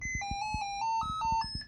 When I turned it back on, it beeped at me.